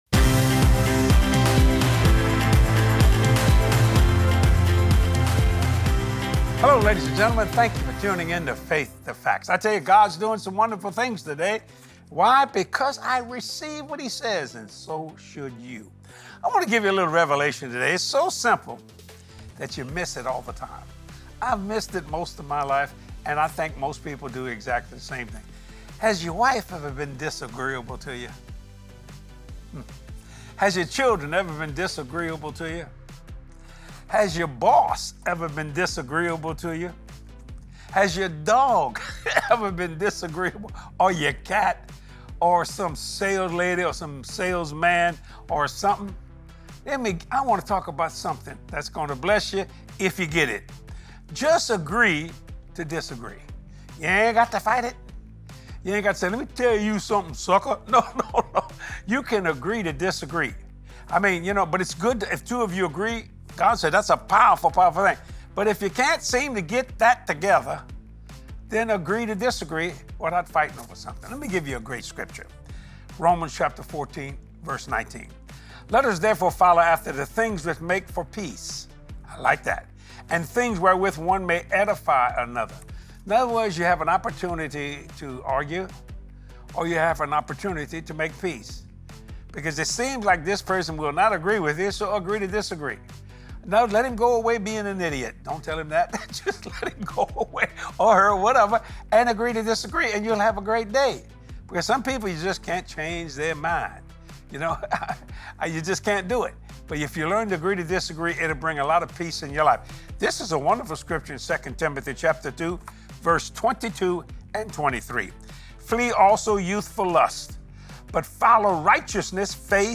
Always pursue PEACE in your relationships! Watch this revelatory message from Jesse and learn that sometimes it’s best to just agree to disagree.